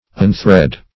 Unthread \Un*thread"\, v. t. [1st pref. un- + thread.]